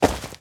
SFX_saltoSacos1.wav